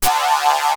crunk efx.wav